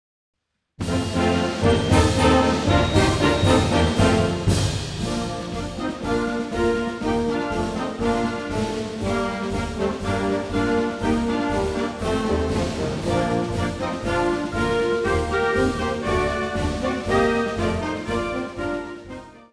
Besetzungsart/Infos 4Part; Perc (Schlaginstrument)